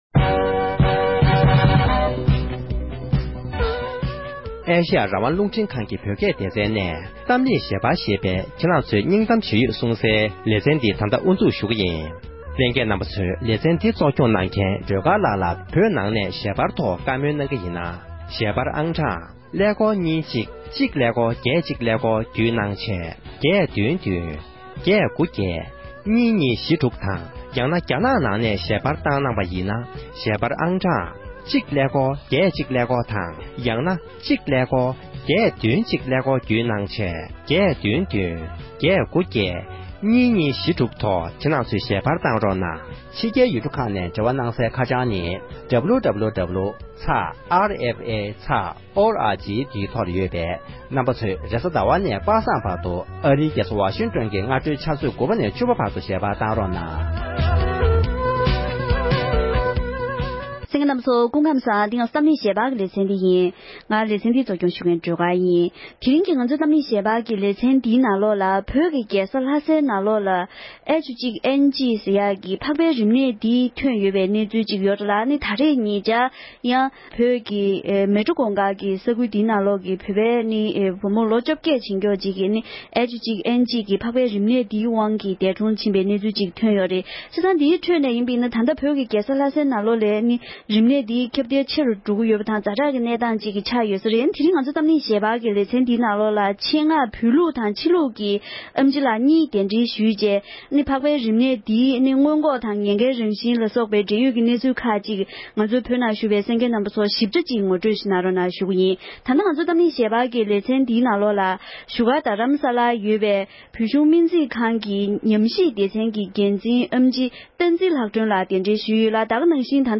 གཏམ་གླེང་ཞལ་པར